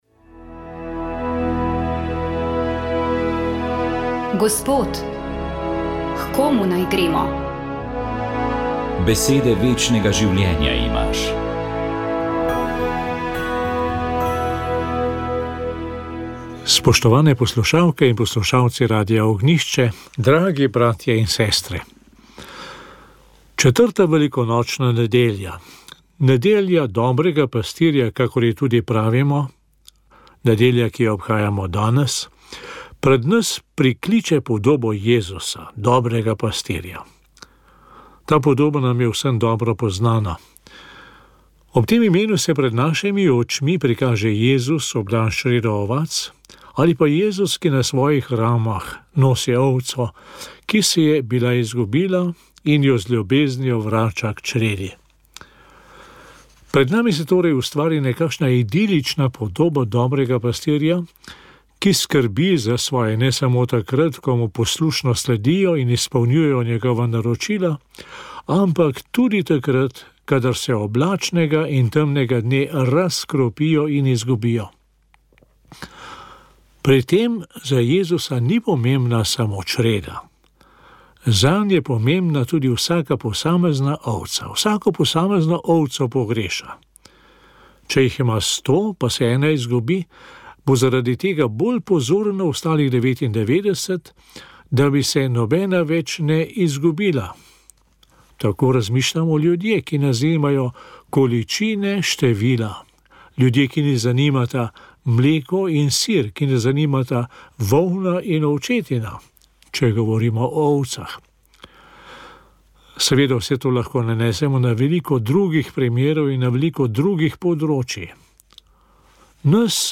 Duhovni nagovor za 1. postno nedeljo je pripravil murskosoboški škof Peter Štumpf.